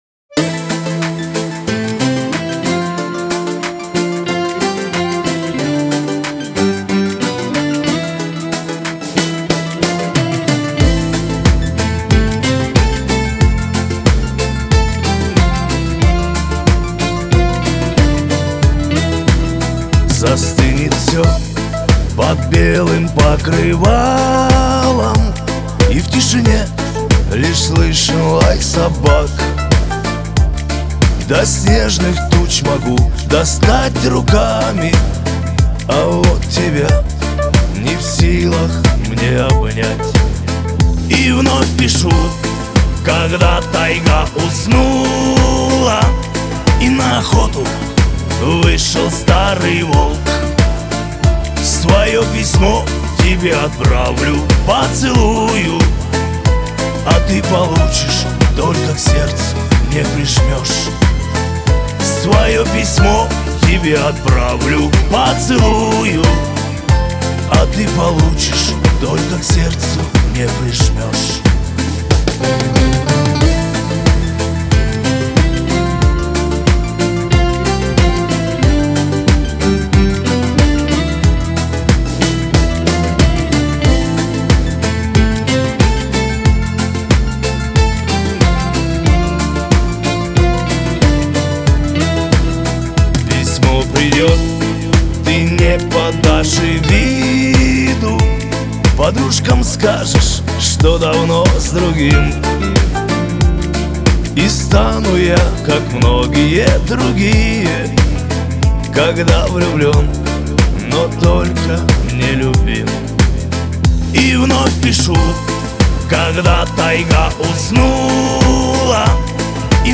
это яркий пример русского шансона
Настроение песни – melancholic, но с нотами надежды и любви.